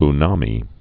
(-nämē, y-nămē)